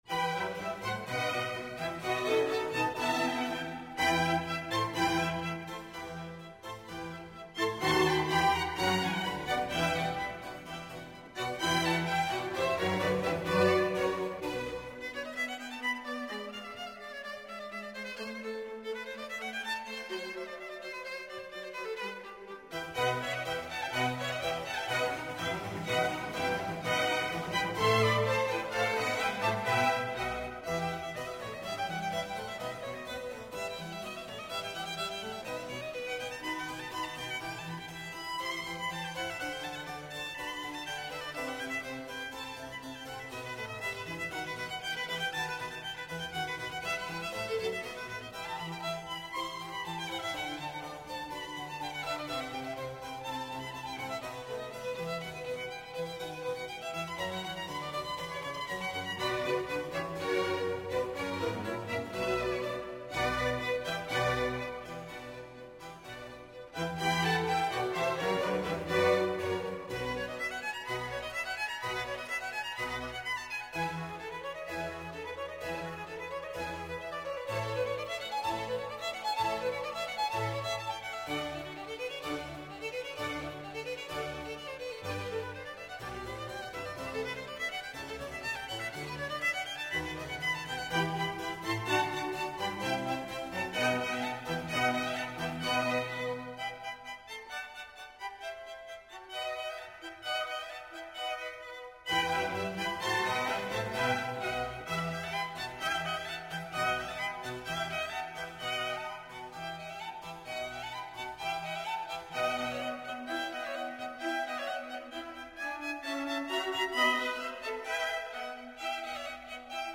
vivaldi-concerto-for-4-violins-in-e-minor.mp3